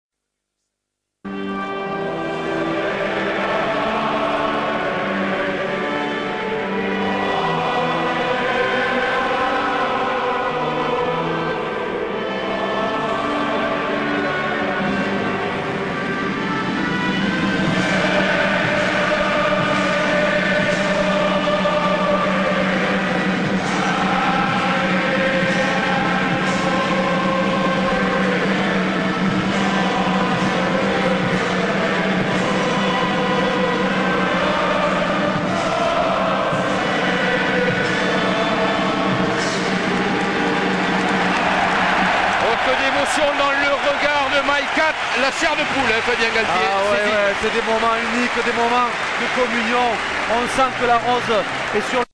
god-save-the-queen-twickenham-vs-france.mp3